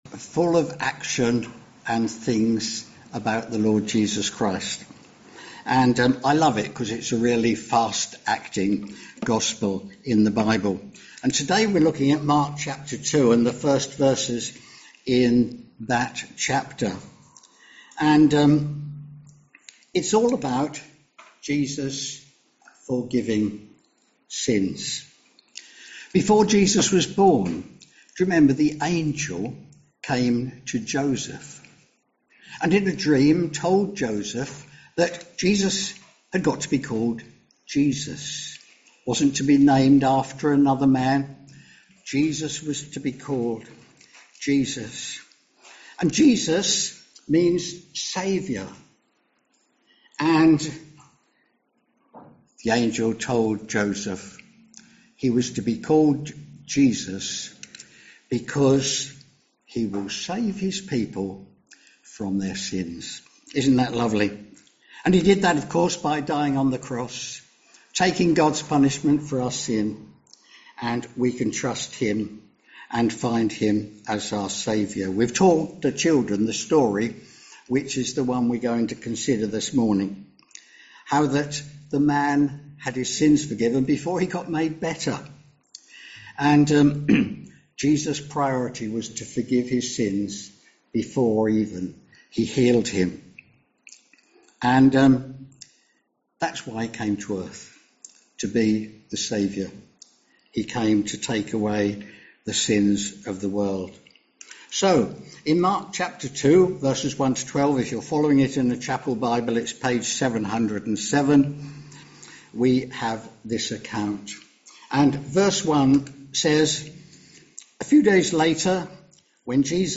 Sermons - Swanfield Chapel